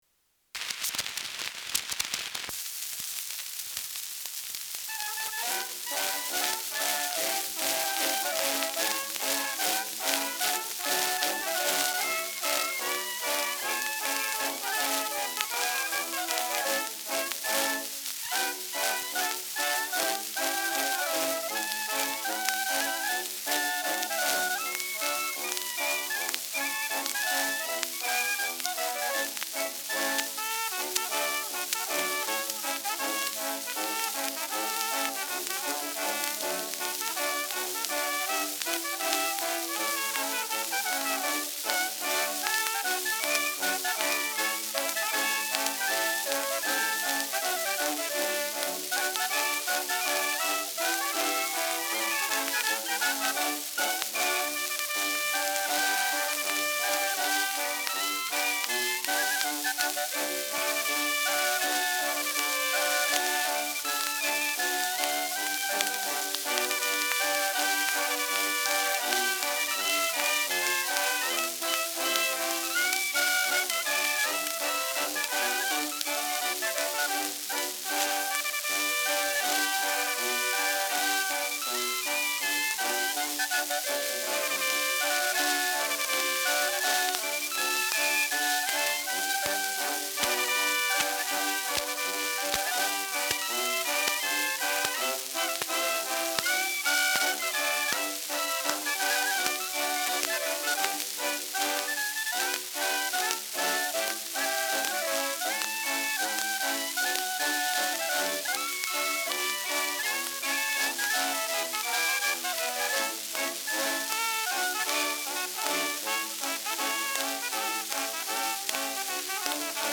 Schellackplatte
Stark abgespielt : stärkeres Grundrauschen : gelegentlich leichtes Knacken : dünner Klang : Nadelgeräusch im zweiten Drittel
Kapelle Die Alten, Alfeld (Interpretation)